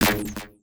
Universal UI SFX / Clicks
UIClick_Laser Double Impact 03.wav